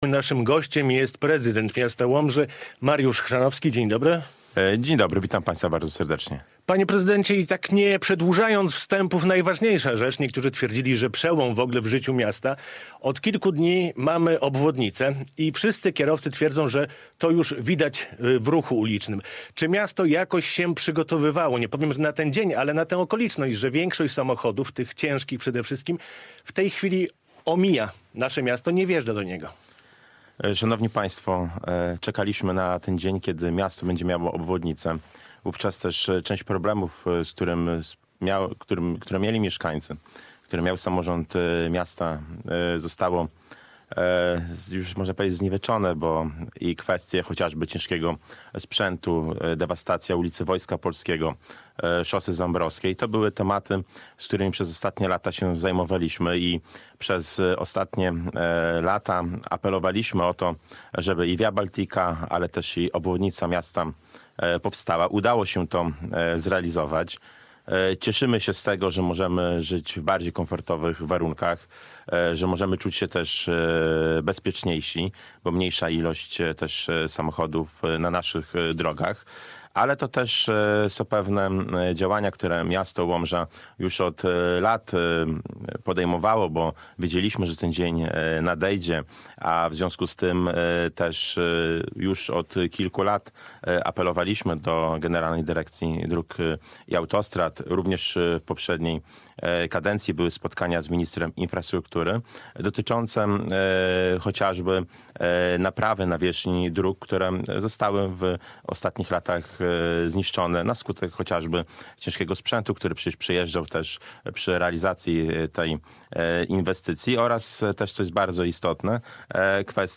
Radio Białystok | Gość | Mariusz Chrzanowski - prezydent Łomży